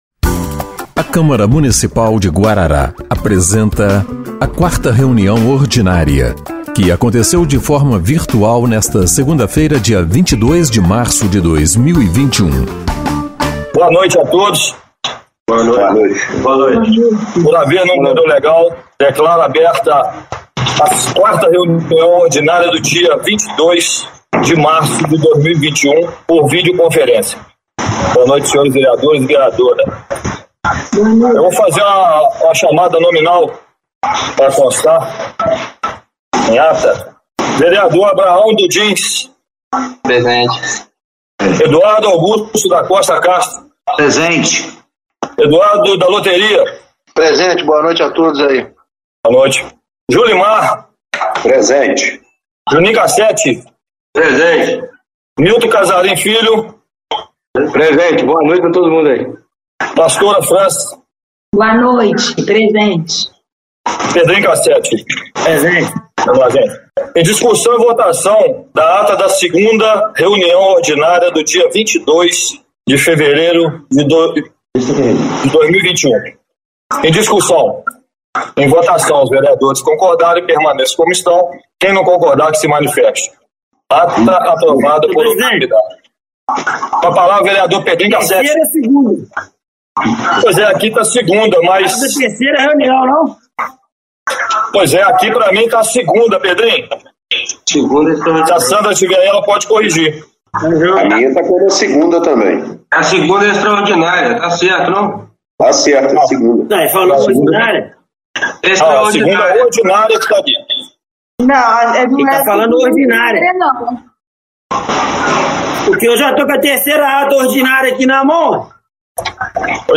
4ª Reunião Ordinária de 22/03/2021